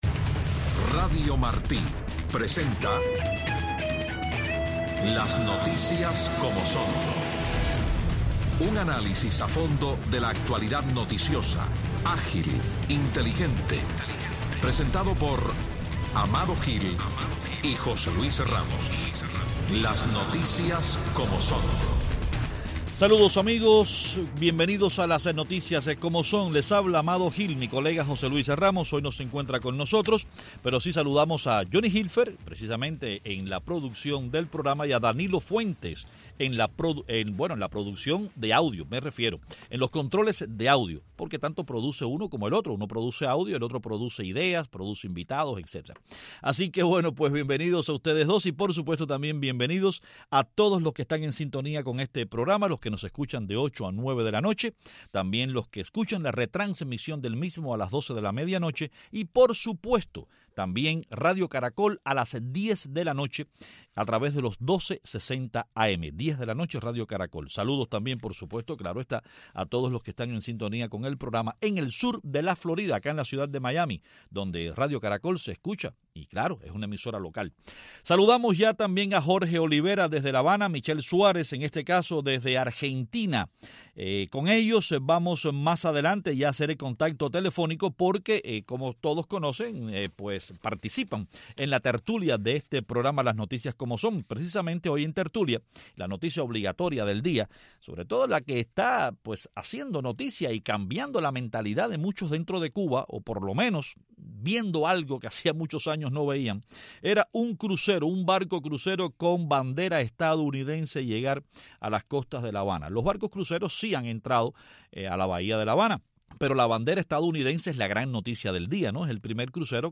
En Tertulia